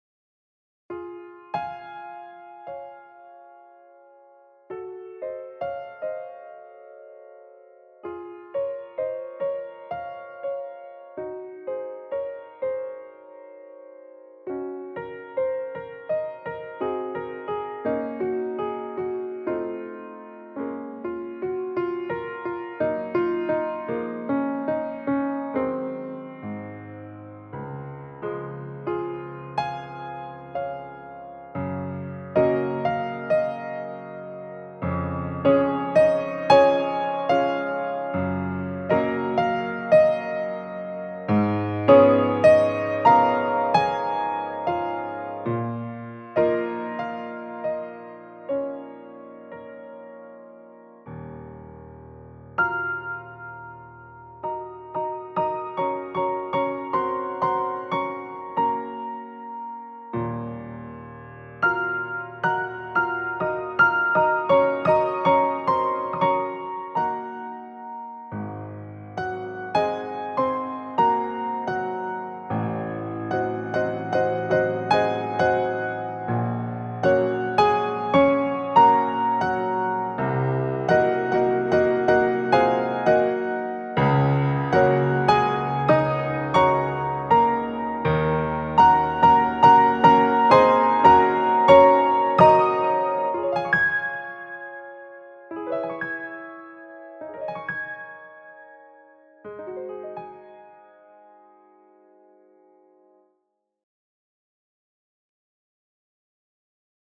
In diesem kostenlosen 3,5 GB (!) Grand Piano sind keine separaten zumischbaren Release-Geräuschsamples dabei. Mich stört das nicht wirklich, das ist soweit alles sauber.
Melda Grand Piano Hörbeispiel: kann man nicht meckern, find ich.